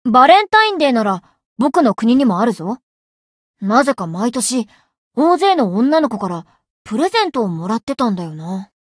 灵魂潮汐-莉莉艾洛-情人节（相伴语音）.ogg